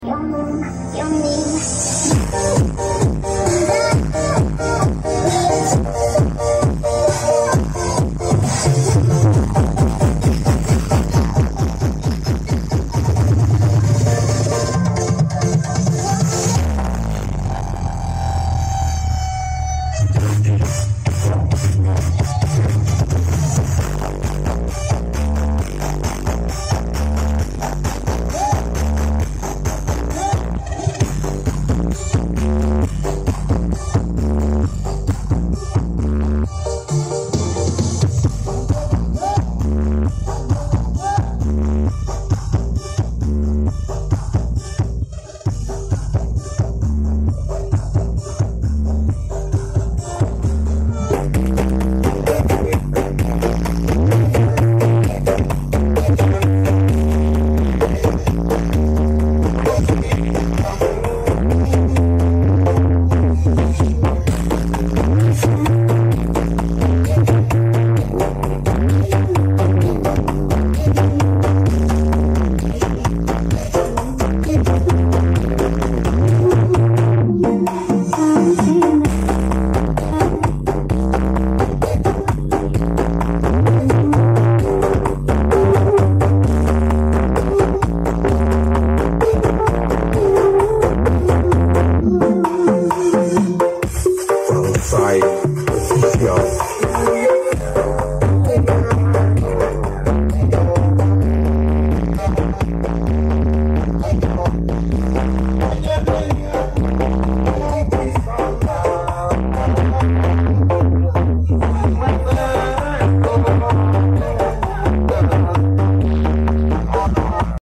Karnaval kalimanis doko blitar......!!!!